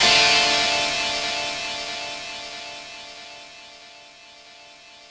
ChordDm.wav